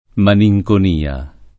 DOP: Dizionario di Ortografia e Pronunzia della lingua italiana
malinconia [malijkon&a] s. f. — lett. melanconia [melajkon&a] e antiq. melancolia [melajkol&a], nel sign. corrente di «mestizia» — quasi solo queste due forme, invece, nell’uso psichiatrico («malattia psichica») — nell’uso più ant. (fino a tutto il ’500), numerose altre varianti di forma, tra cui più com. (e insieme, per effetto di etimologie pop., più lontana dall’origine greca) maninconia [